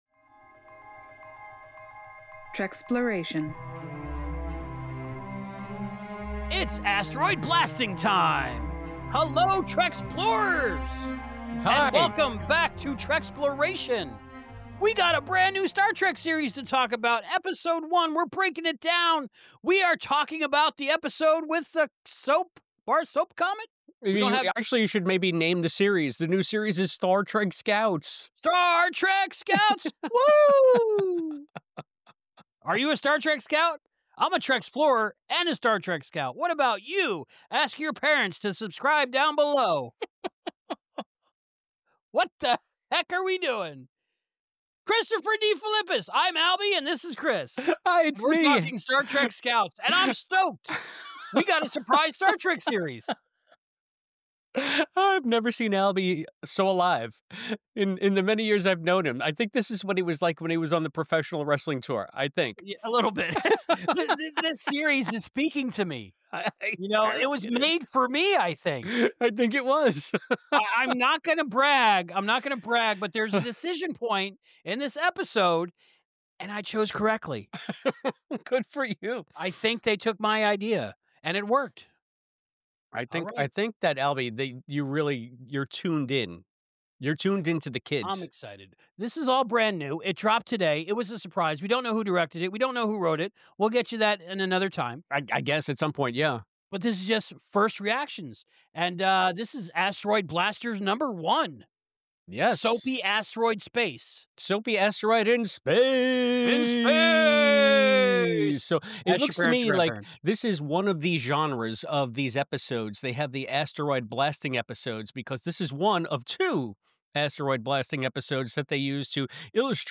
Scouts Episode 1 REVIEW “SOAPY Asteroid in Space!” NOT FOR KIDS